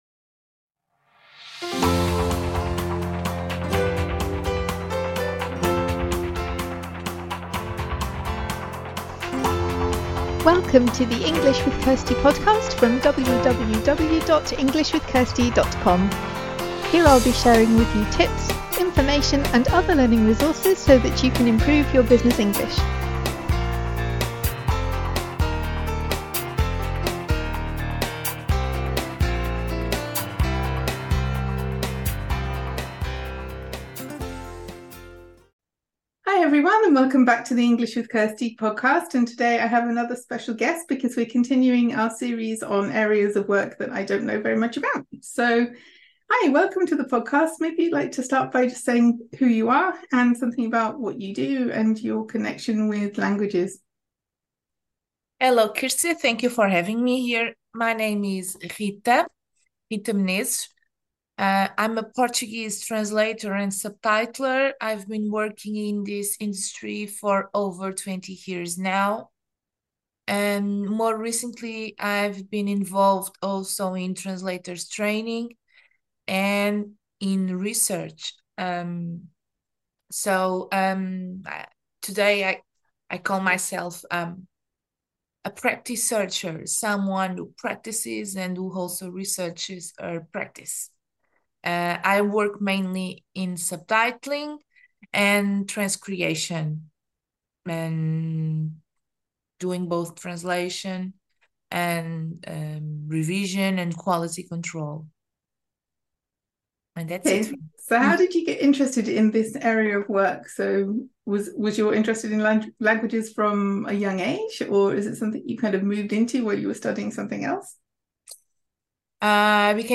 My guest today